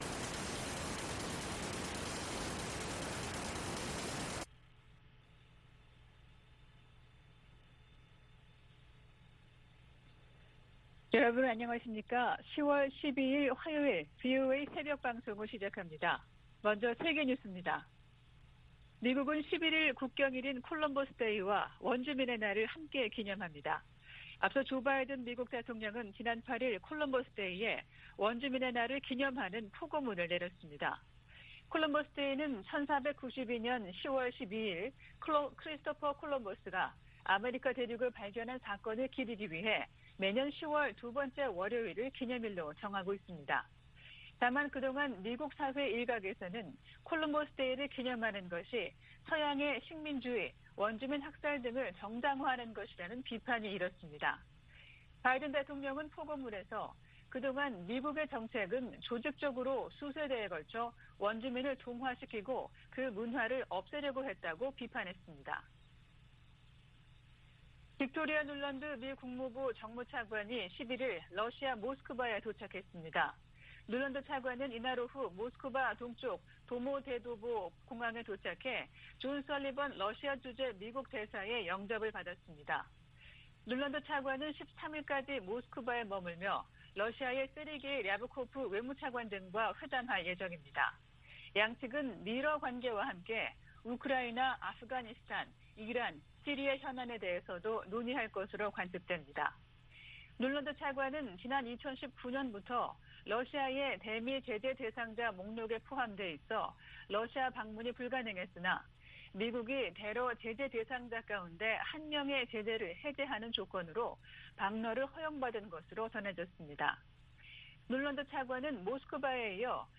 VOA 한국어 '출발 뉴스 쇼', 2021년 10월 12일 방송입니다. 북한이 남북 통신연락선을 복원한 지 일주일이 지났지만 대화에 나설 조짐은 보이지 않고 있습니다. 미국은 지난해 정찰위성 2개를 새로 운용해 대북 정보수집에 활용하고 있다고 미 국가정찰국이 밝혔습니다.